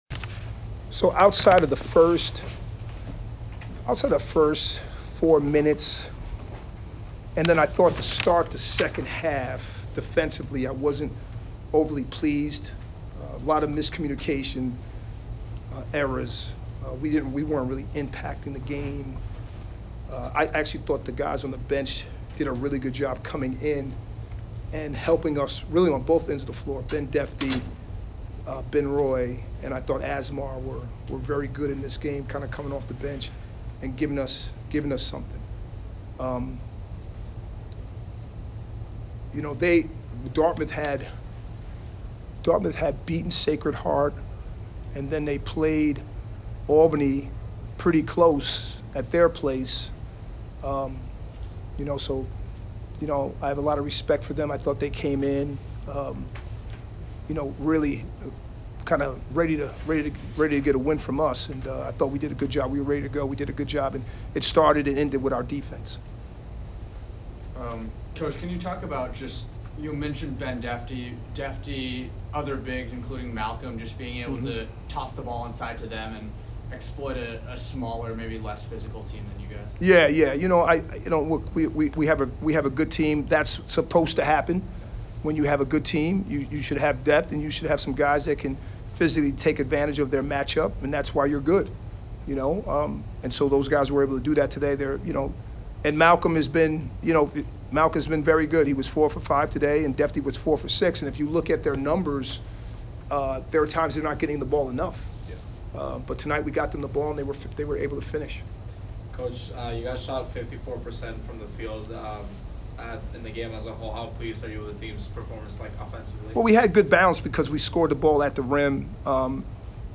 Men's Basketball / Dartmouth Postgame Interview (11-16-24) - Boston University Athletics